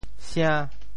« 城 » quel est le mot en Teochew ?
城 部首拼音 部首 土 总笔划 9 部外笔划 6 普通话 chéng 潮州发音 潮州 sian5 白 潮阳 sian5 白 澄海 sian5 白 揭阳 sian5 白 饶平 sian5 白 汕头 sian5 白 中文解释 潮州 sian5 白 对应普通话: chéng ①围绕都市的高墙：～墙 | ～池 | ～圈 | ～郭（“城”指内城墙，“郭”指外城墙） | ～楼 | ～堞（城上的矮墙，亦称“女儿墙”） | ～垛。